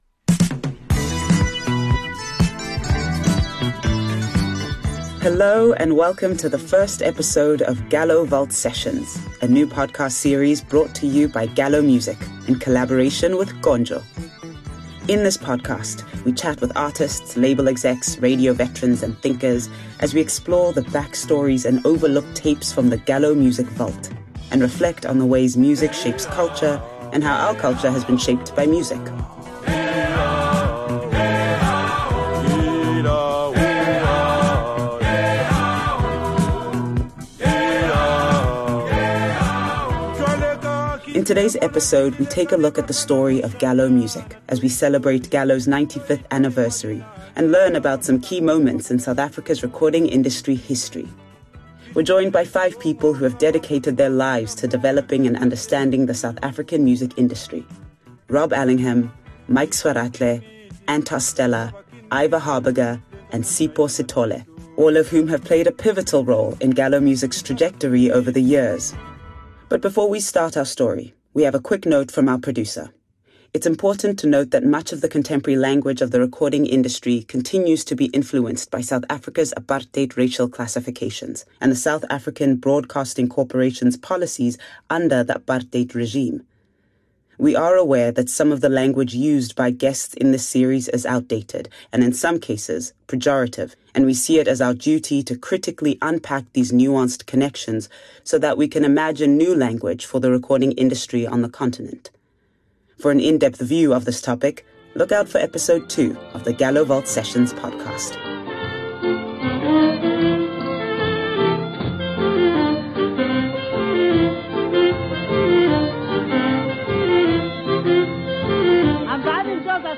In this episode of Gallo Vault Sessions we take a look at the story of Gallo Music as we celebrate Gallo Music’s 95th anniversary and learn about some of the key moments in South Africa’s recording industry history! We’re joined by 5 people who have dedicated their lives to developing and understanding the South African music industry and its history